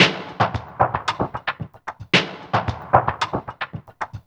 DWS SWEEP3.wav